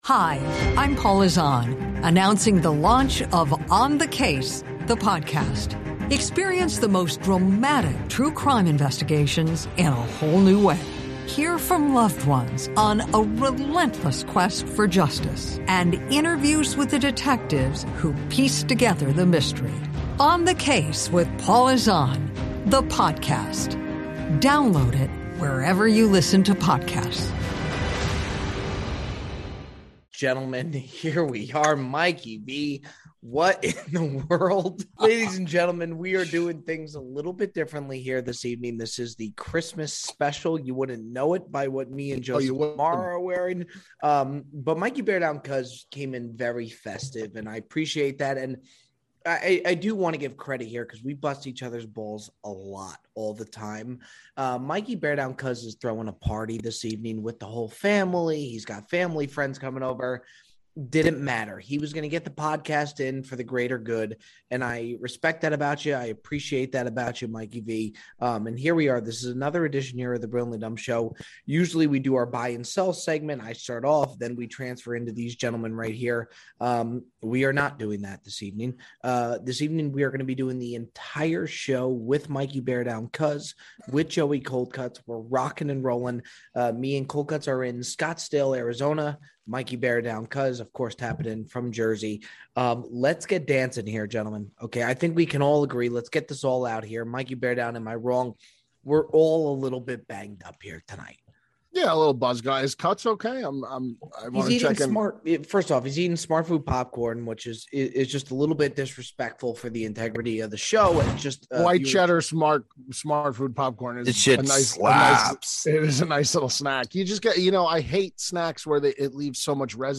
Live From Scottsdale